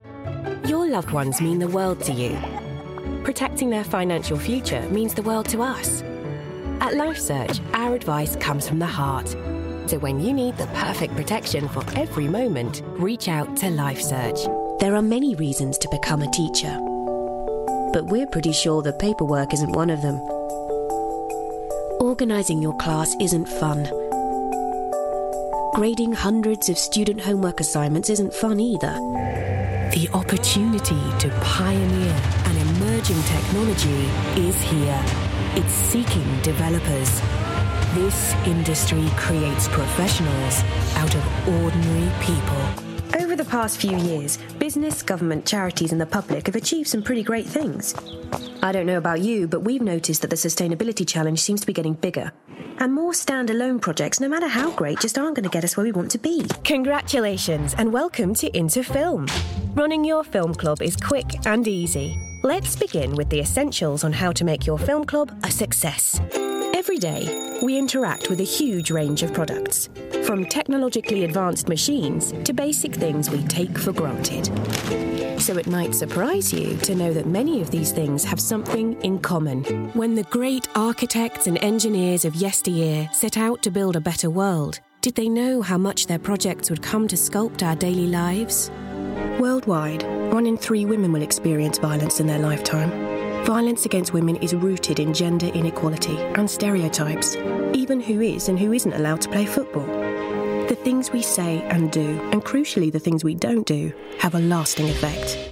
English (British)
Corporate Videos
My warm, authentic and yet professional tone is a popular choice with clients. I'm versatile, easy to work with and my broadcast quality studio wont let you down.
Mezzo-Soprano